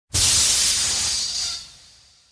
gas.ogg